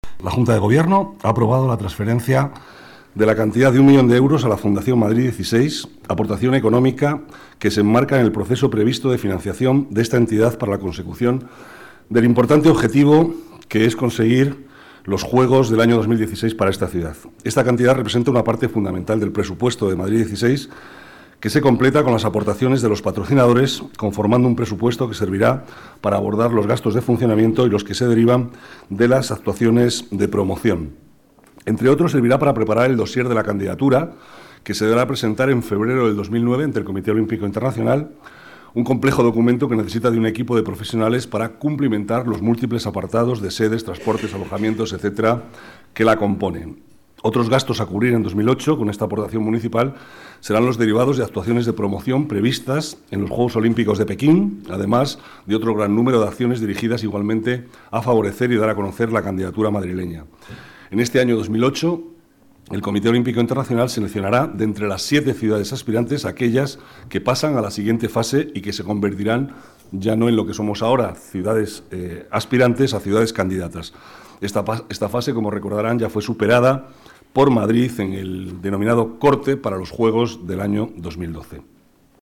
Nueva ventana:Declaraciones vicealcalde, Manuel Cobo: dossier olímpico madrid 2016